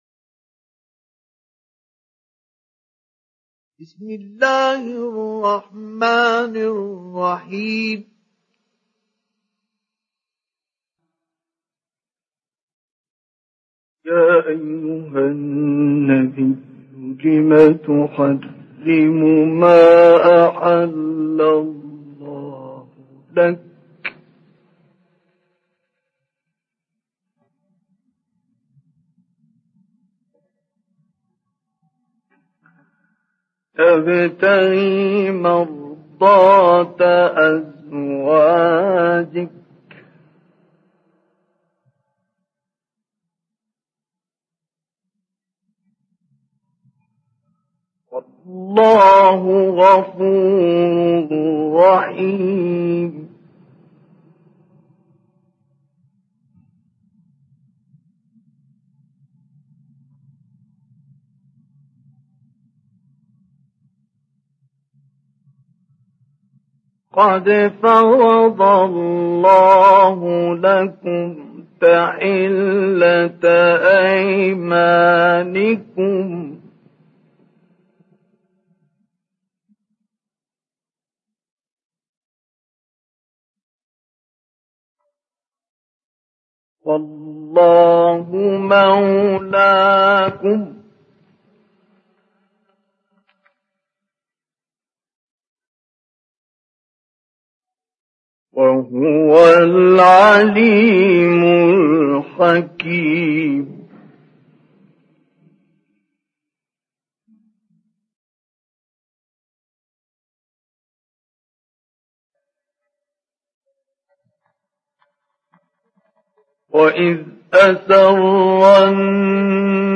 Download Surah At Tahrim Mustafa Ismail Mujawwad